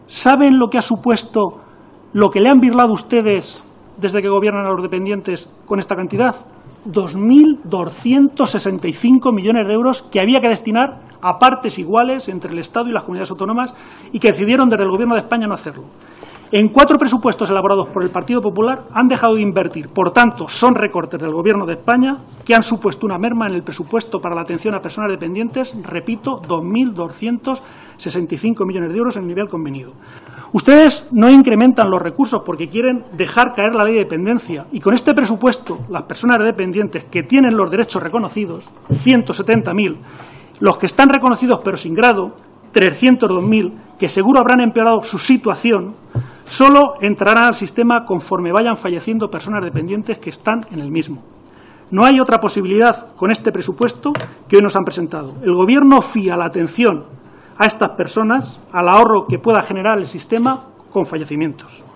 Fragmento de la intervención de Luis Carlos Sahuquillo en el Comisión de Sanidad y Servicios Sociales.